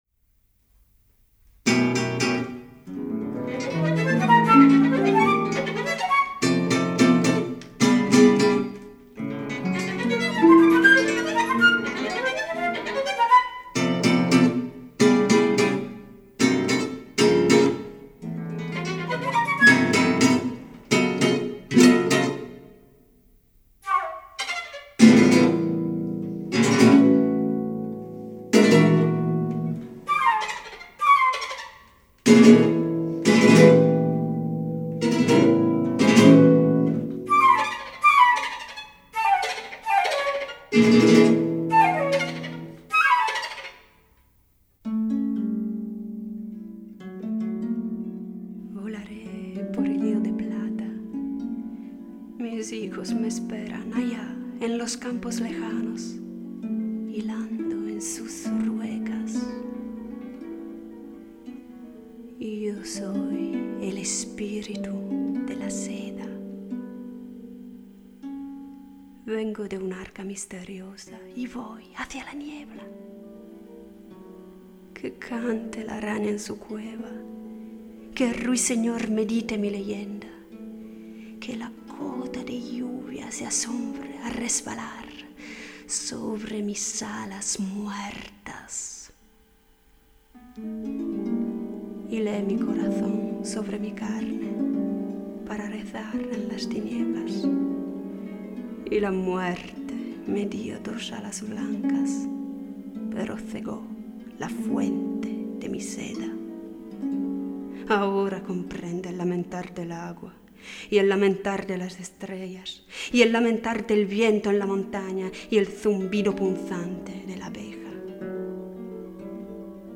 Musica per balletto
Per voce recitante e canto
flauto e ottavino
arpa
oboe
violino
pianoforte e clavicembalo
chitarra e percussioni
La marcha fúnebre se va alejando poco a poco.